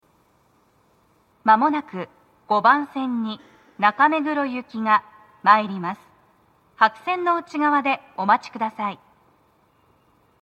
鳴動は、やや遅めでした。
女声
接近放送1